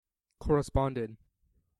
/ˌkɔrʌˈspɑndɪd(米国英語), ˌkɔ:rʌˈspɑ:ndɪd(英国英語)/